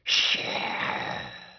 DATrem2_Hiss.wav